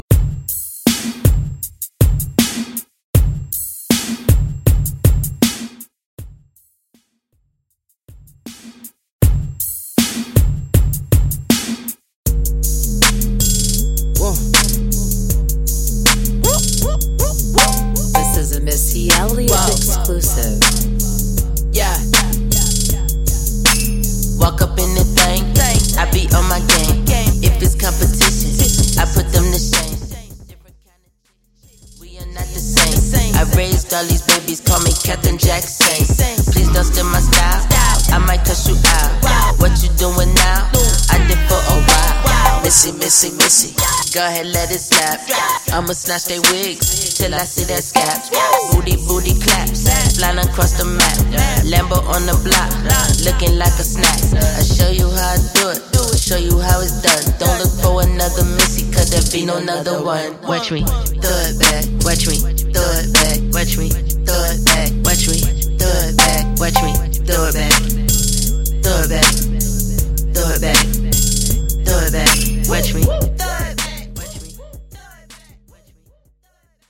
LATIN , RE-DRUM , TOP40